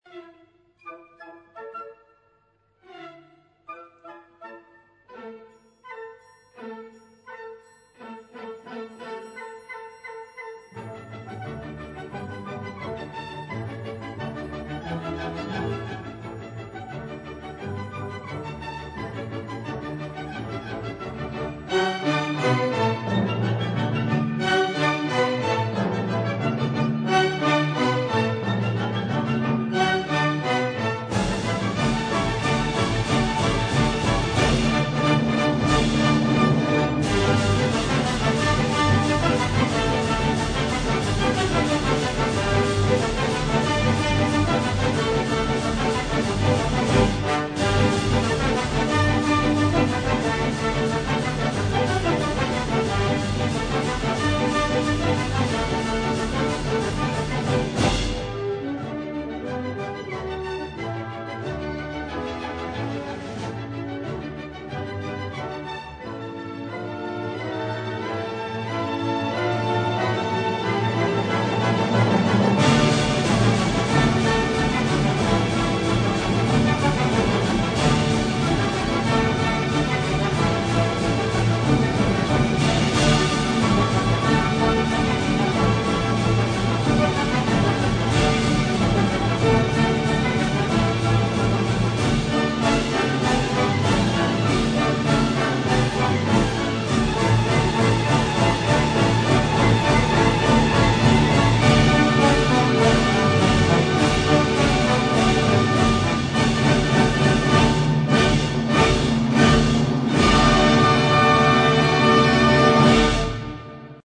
Orphée aux enfers, ópera bufa de J. Offenbach (1858) con el famoso Can-Can.